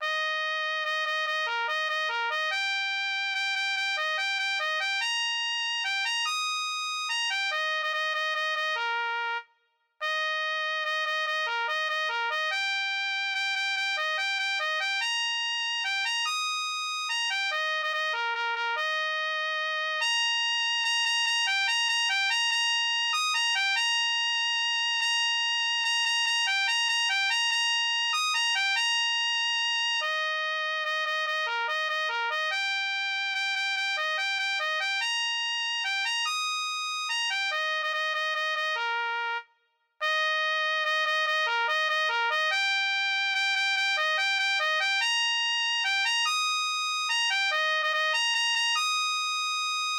A=Melody-for more experienced players